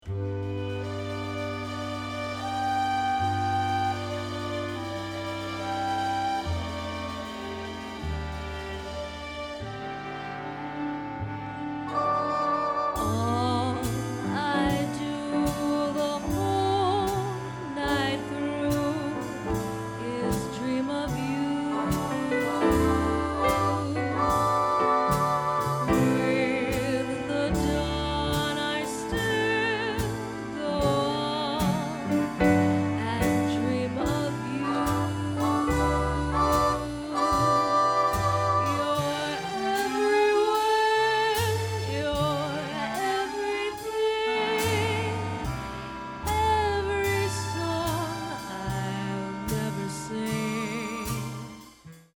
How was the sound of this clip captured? Recorded Musical Examples